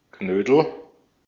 Knödel (German: [ˈknøːdl̩]
De-at-Knödel.ogg.mp3